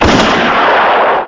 gun shot noise.
002firegun_low.mp3